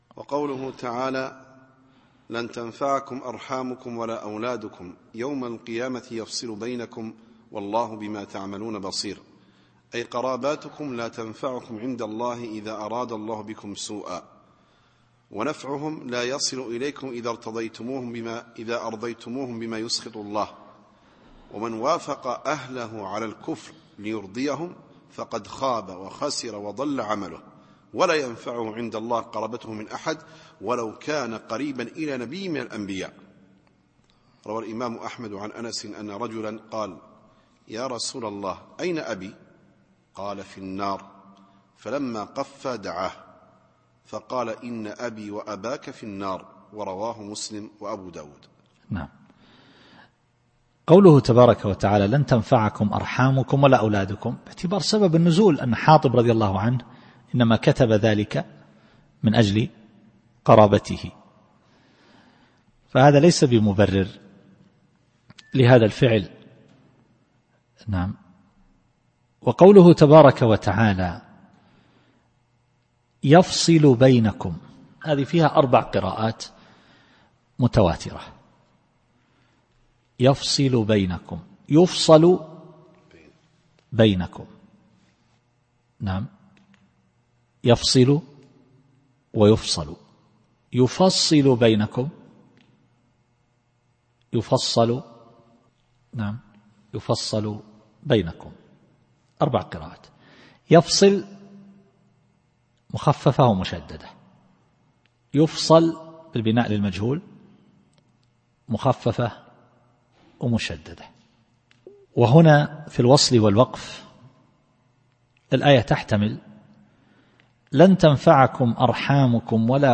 التفسير الصوتي [الممتحنة / 3]